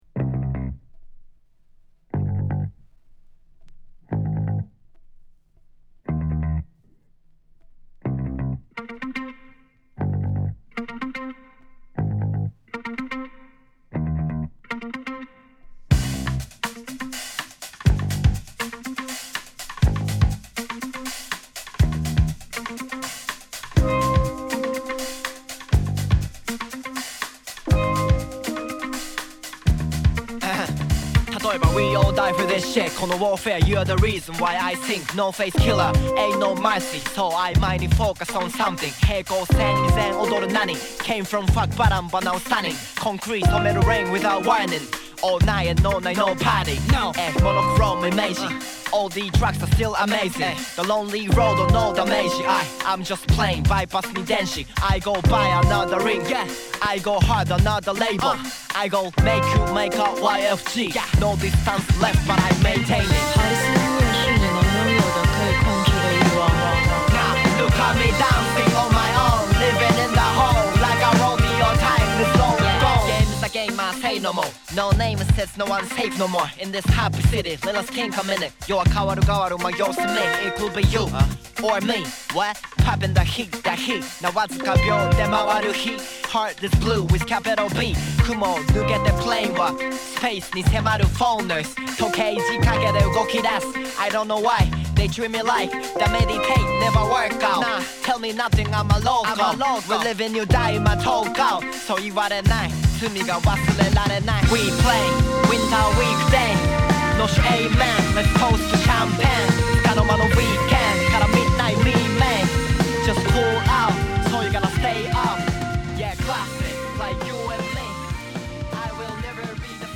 bass
guitar
steelpan